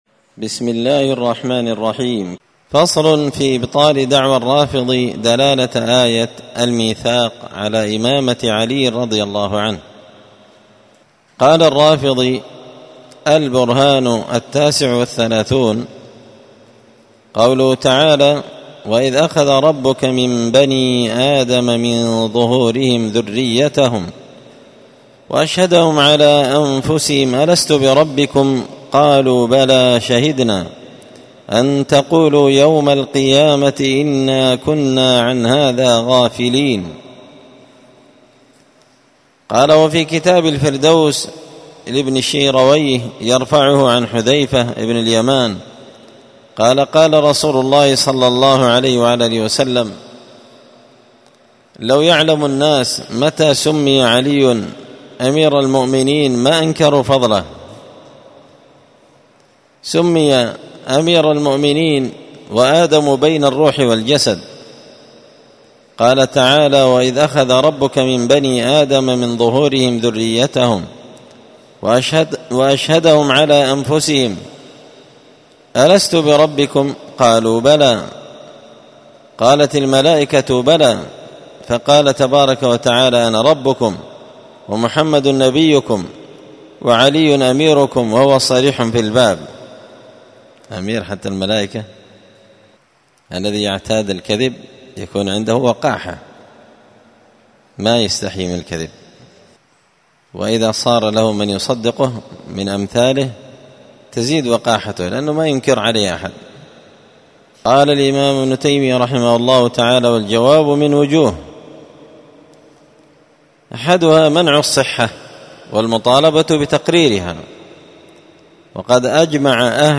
الأربعاء 21 صفر 1445 هــــ | الدروس، دروس الردود، مختصر منهاج السنة النبوية لشيخ الإسلام ابن تيمية | شارك بتعليقك | 20 المشاهدات
مسجد الفرقان قشن_المهرة_اليمن